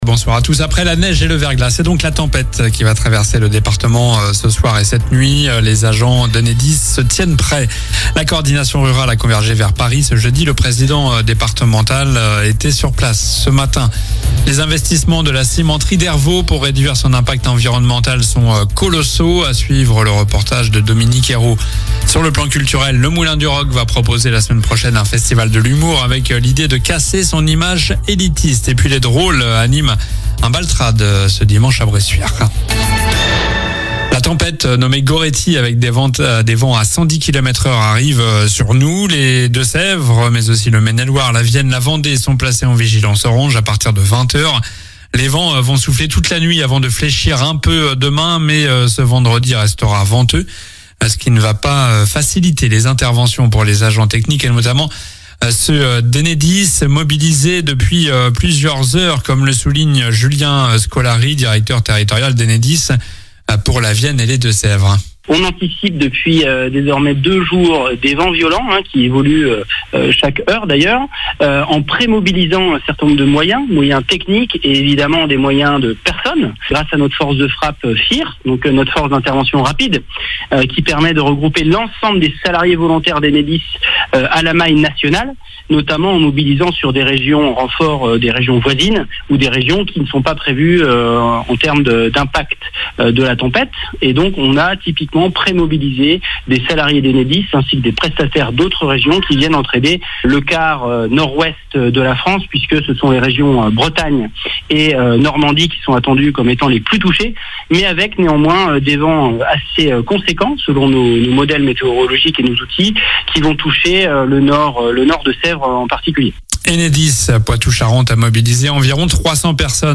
Journal du jeudi 8 janvier (soir)